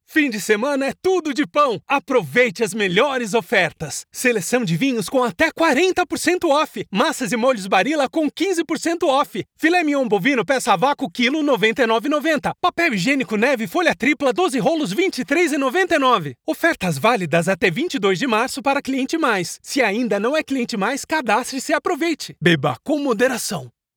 Annonces
Ma configuration de studio à la pointe de la technologie garantit une qualité sonore exceptionnelle pour chaque projet.
Cabine acoustique parfaite